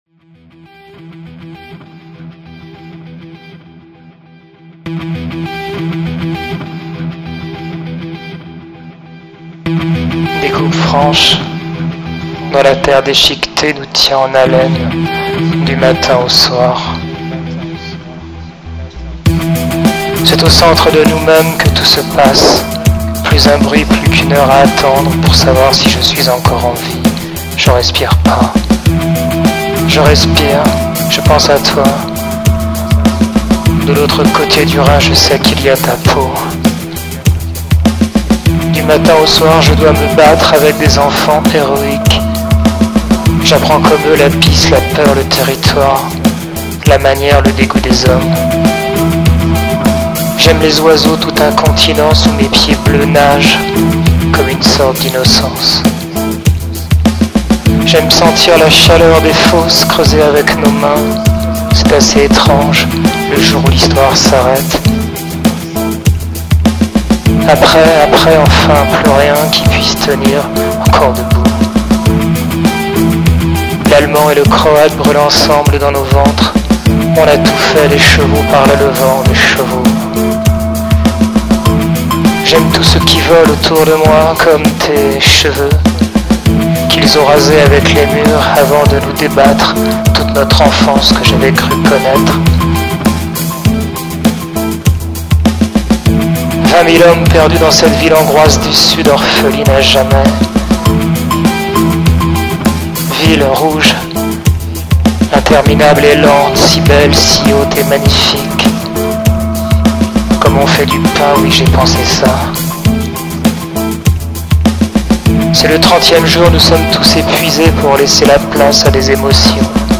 Intermède musical [stigma] ϛ